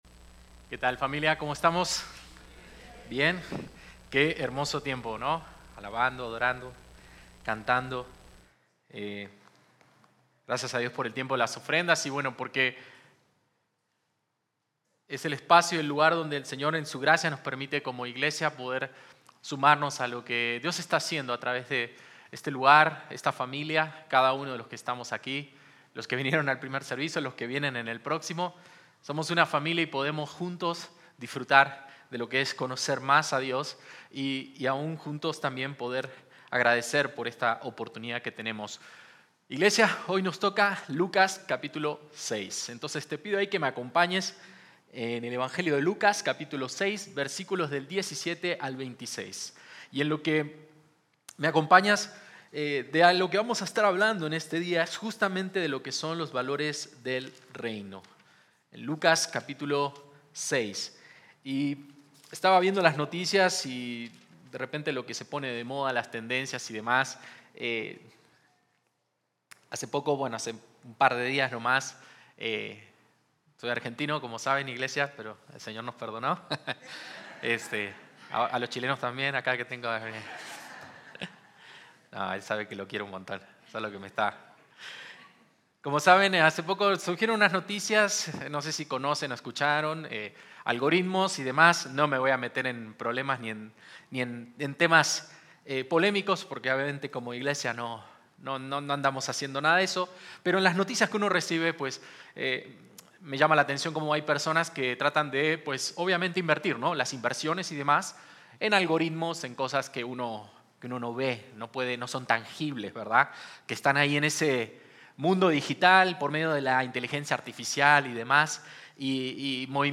Servicio: Domingo